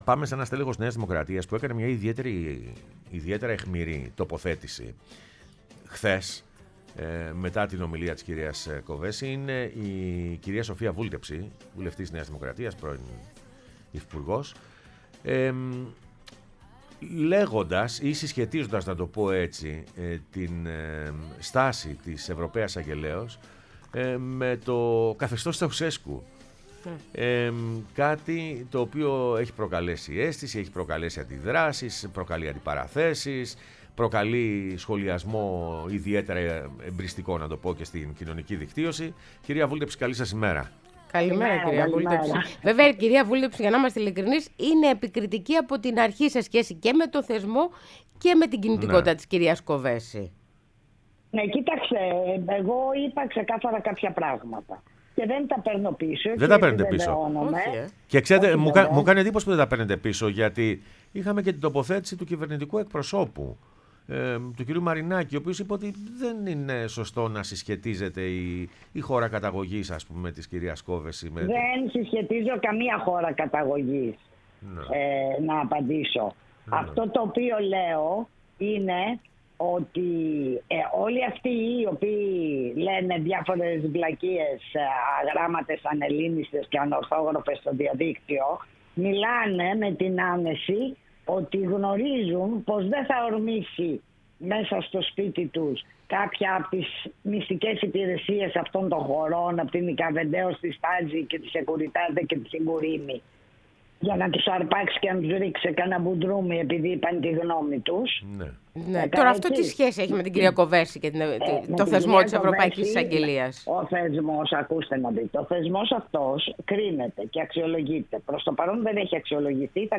Η Σοφία Βούλτεψη, βουλευτής ΝΔ, μίλησε στην εκπομπή «Πρωινές Διαδρομές»